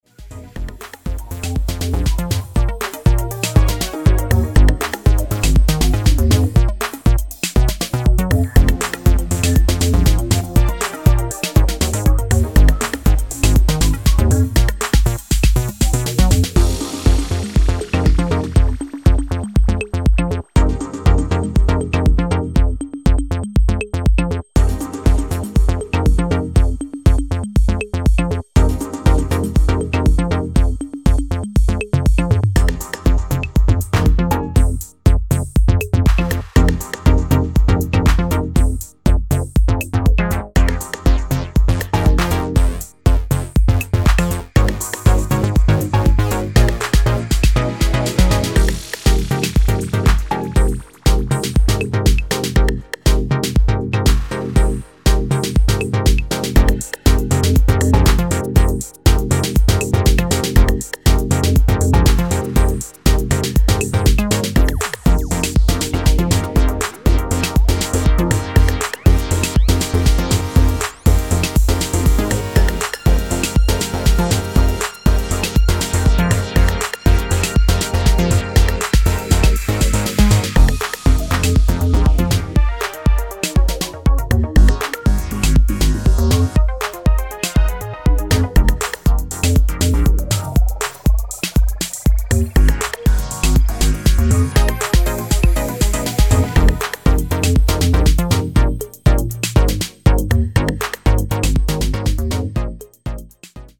耳障りの良いシンセにハマれる秀作です！